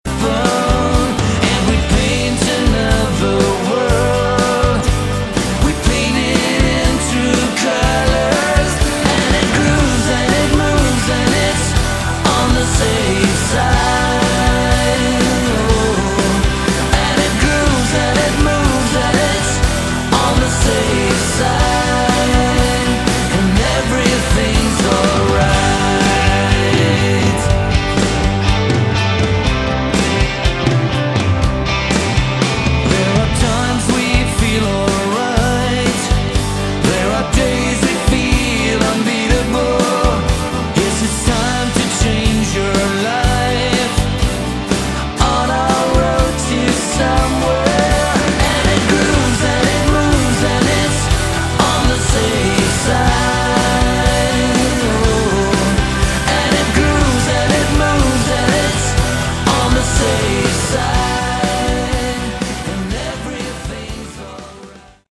Category: AOR
lead and backing vocals
guitar, backing vocals, keyboards, bass
drums, backing vocals
lapsteel